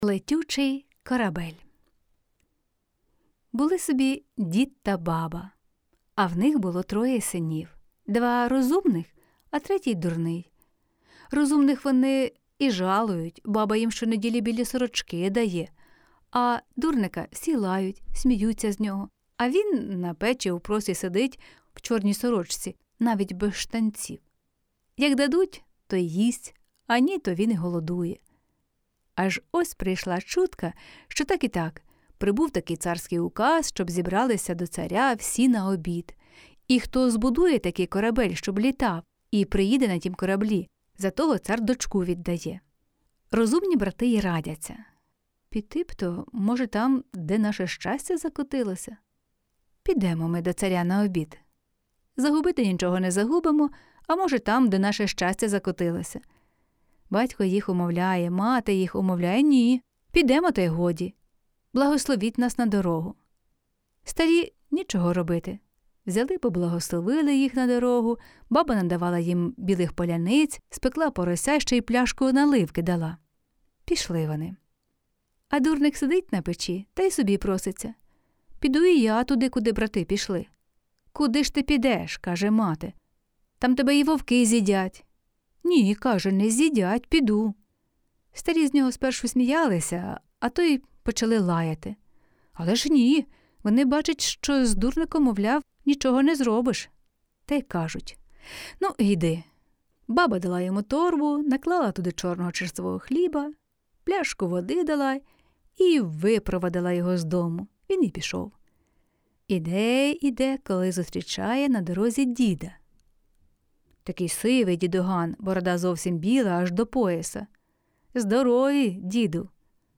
Жанр: Казка на добраніч Автор: Українська казка